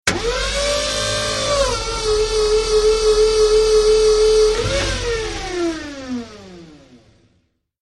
Tuning fork 5
Category: Sound FX   Right: Personal